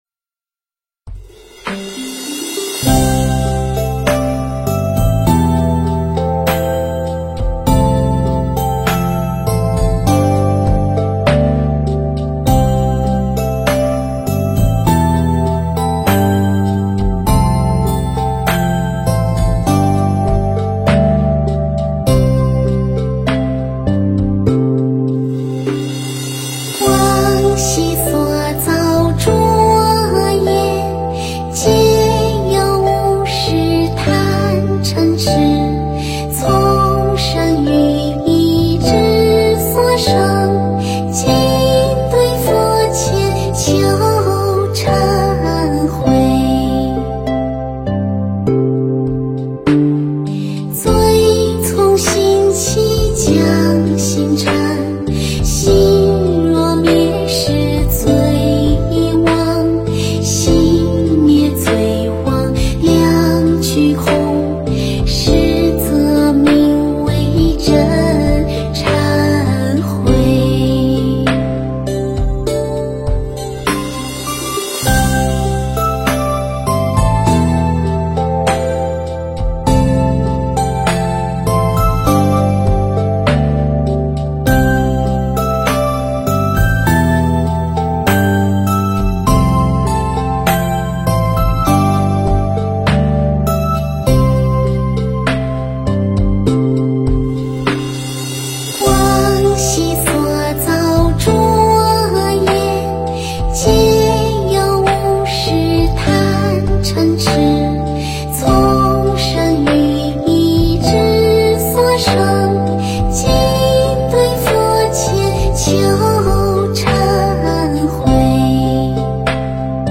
佛音 诵经 佛教音乐 返回列表 上一篇： 晚安曲 下一篇： 灯舞 相关文章 香华献供--佚名 香华献供--佚名...